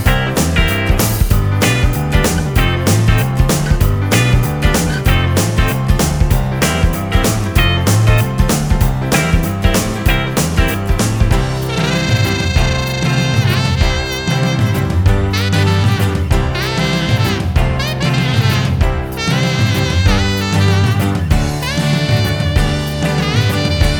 No Backing Vocals Soundtracks 3:27 Buy £1.50